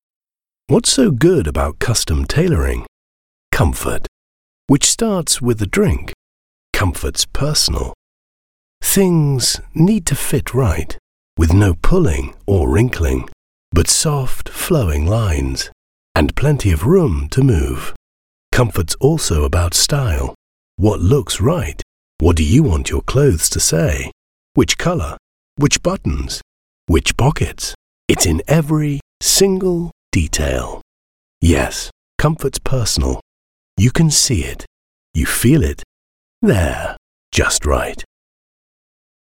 Englisch (Britisch)
Kommerziell, Natürlich, Zuverlässig, Warm, Corporate
Kommerziell